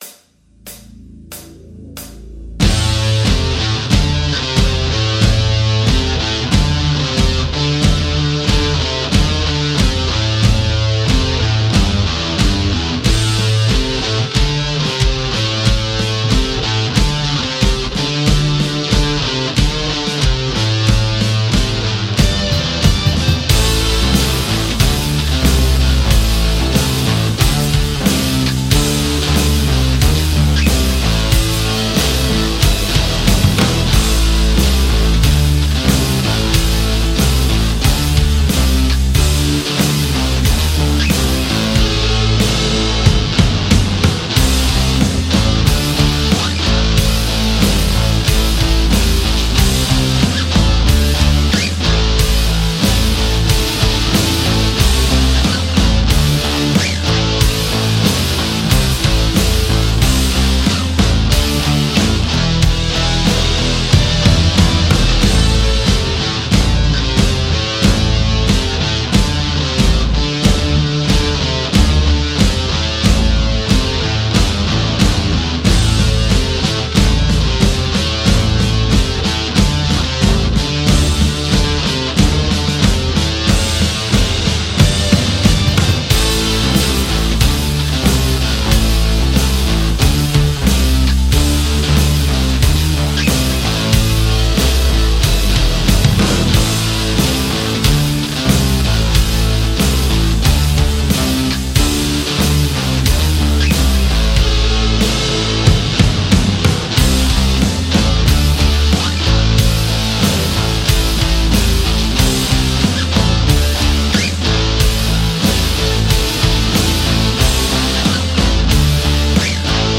• Category: Rock music